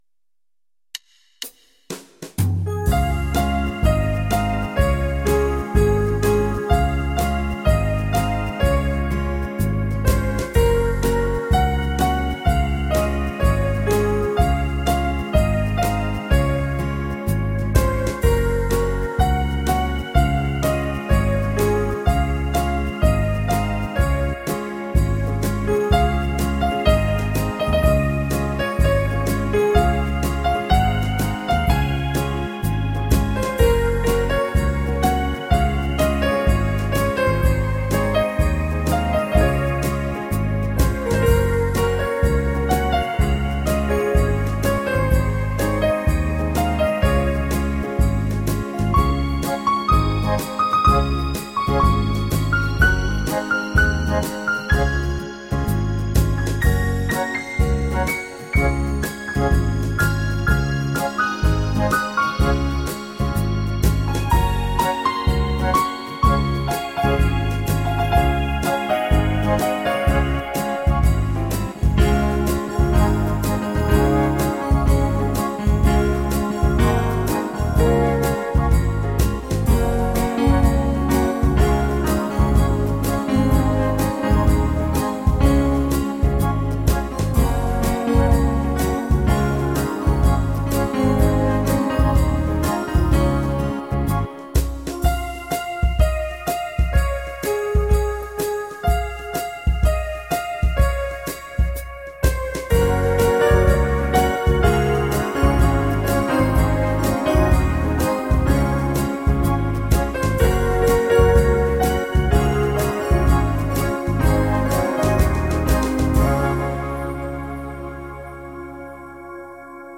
Я для Вас підготував Новорічні музичні композиції у власному виконанні.